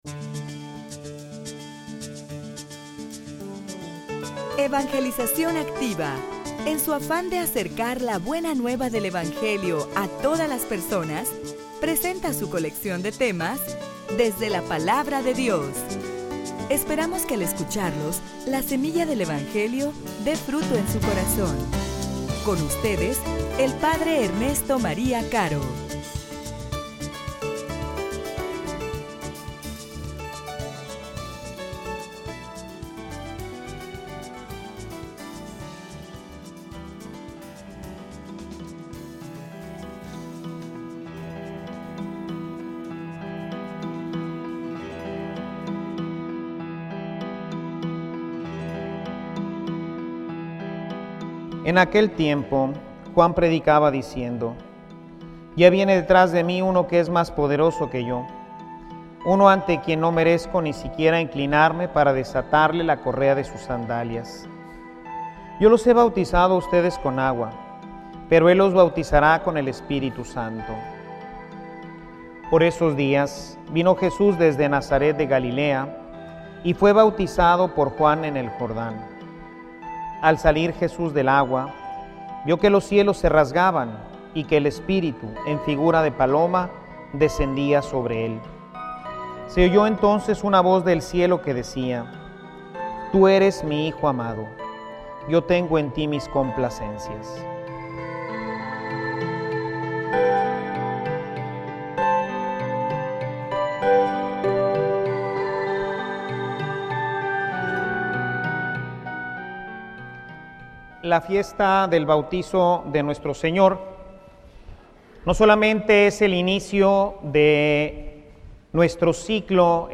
homilia_Somos_hijos_amados_de_Dios.mp3